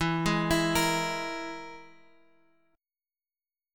E Major Flat 5th